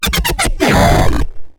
Hero_Death_00
Category 🎮 Gaming
8-bit 8bit Death Die Game Killed Lose Nostalgic sound effect free sound royalty free Gaming